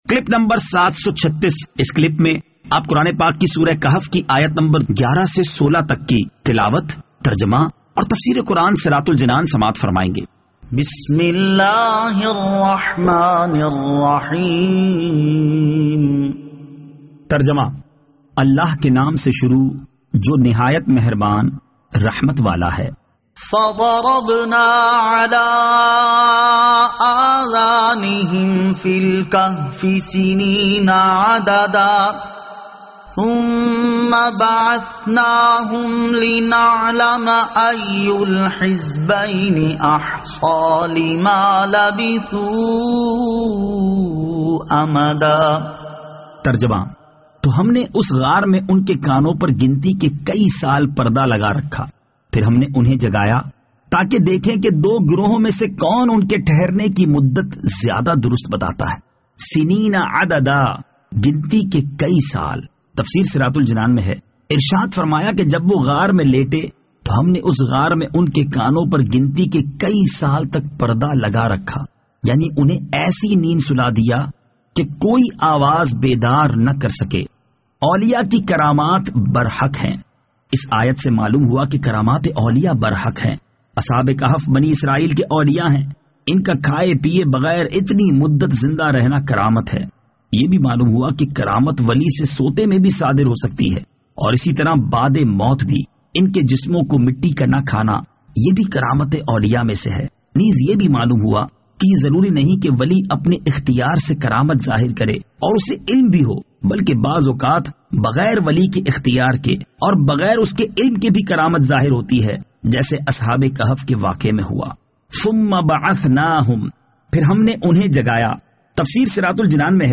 Surah Al-Kahf Ayat 11 To 16 Tilawat , Tarjama , Tafseer